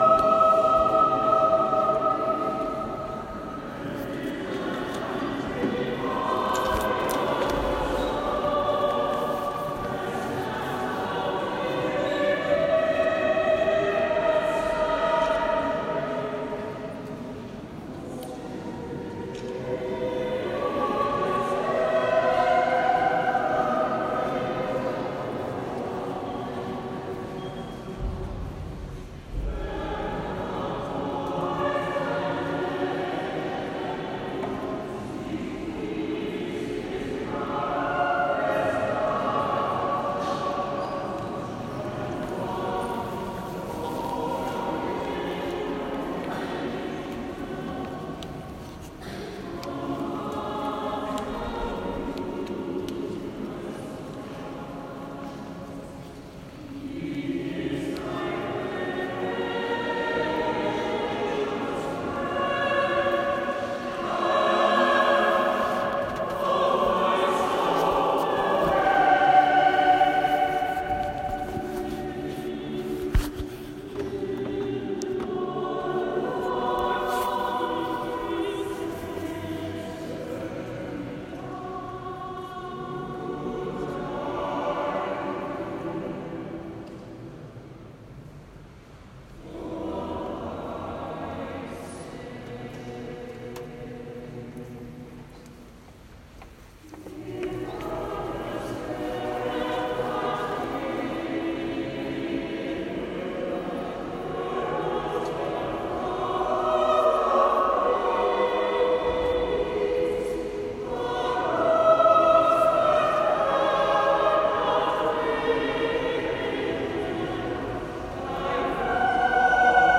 We also had the pleasure of listening to a choir fro Gloucester singing in English which demonstrated the acoustics of this building.  A poor recording (from my iPhone, but gives you an idea.